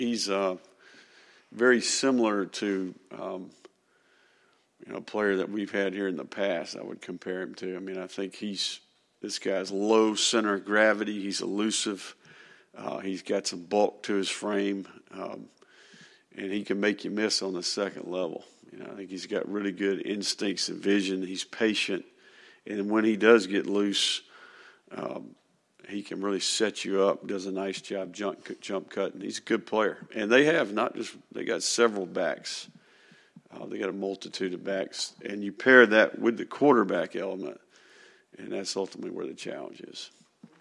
The Gators had intense practices during the bye week in preparation for Saturday’s home game against UCF, Florida football coach Billy Napier said during Monday’s news conference.